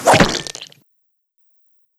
mixkit-sword-slides-a-body-2791.wav